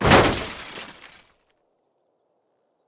car_impact_00.wav